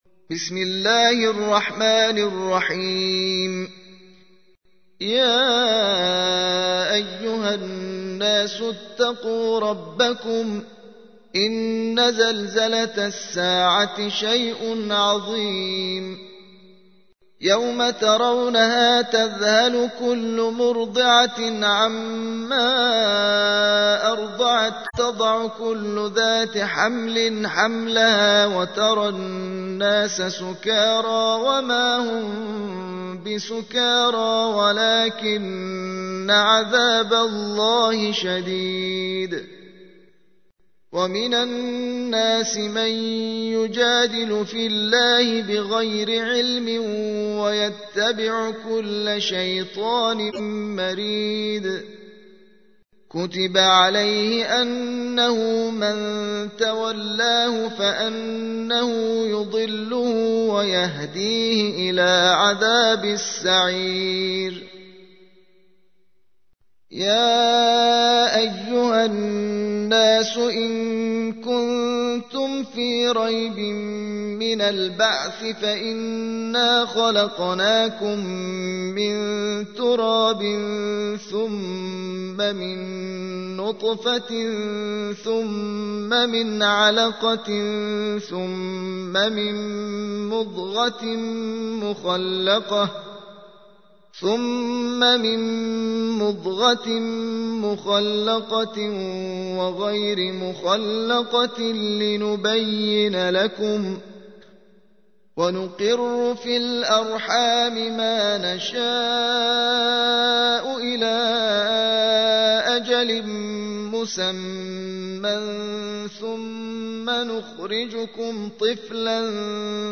تحميل : 22. سورة الحج / القارئ محمد حسين سعيديان / القرآن الكريم / موقع يا حسين